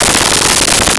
Arcade Movement 04.wav